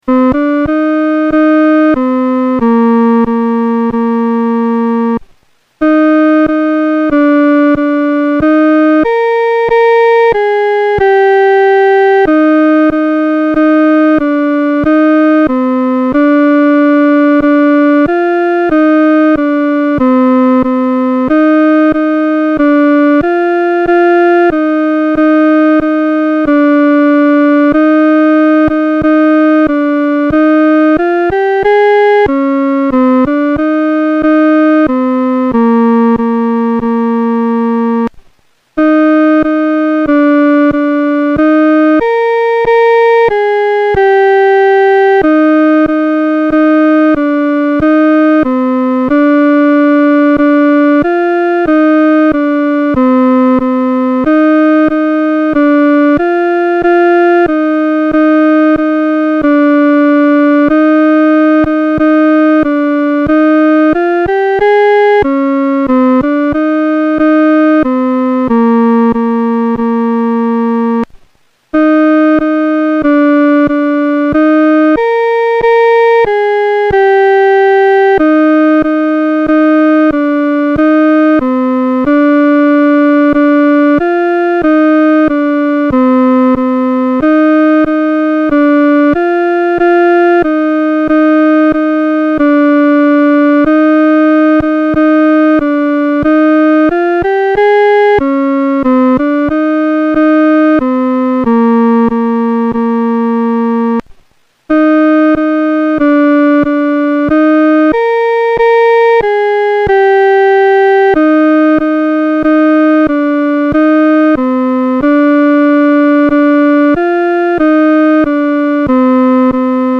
四声
本首圣诗由网上圣诗班 (南京）录制